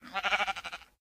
assets / minecraft / sounds / mob / sheep / say1.ogg